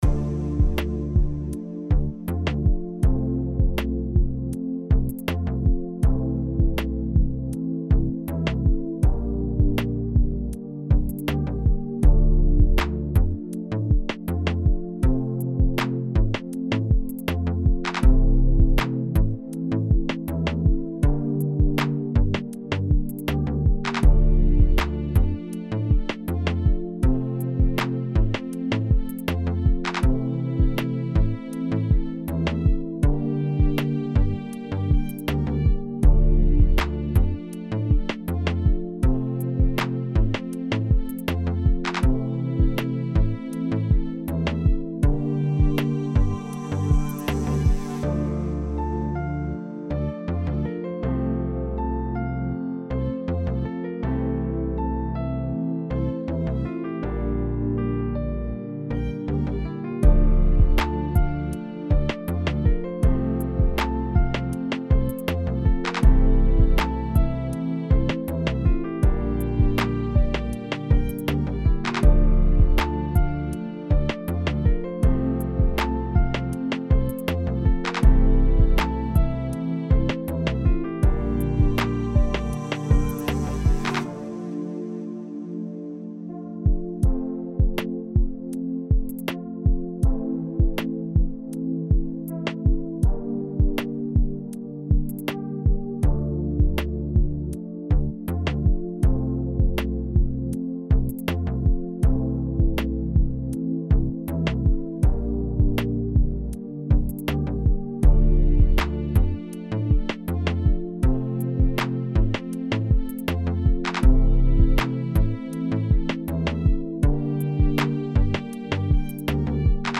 Still in very rough early stages, partner says she loves it, others think it's alright but nothing special.